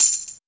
percussion 10.wav